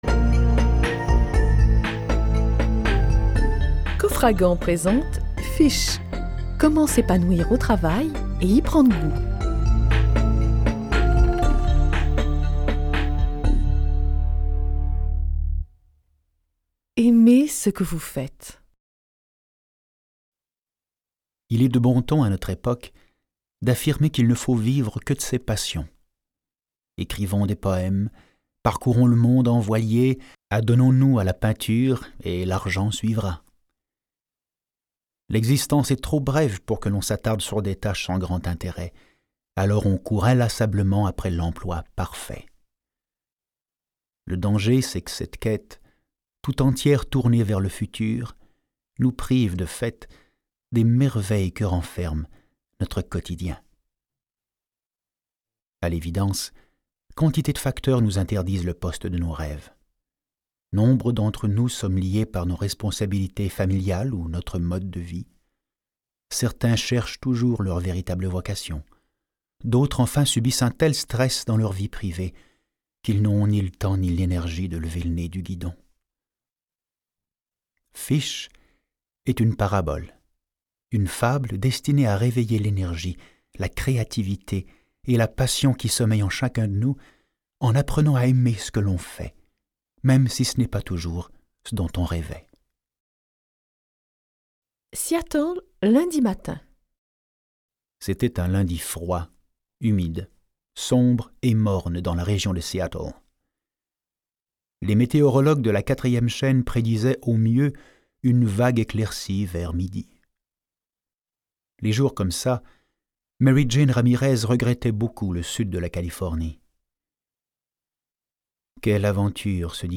Extrait gratuit - Fish de John Christensen, Jacques Lundin, Harry Paul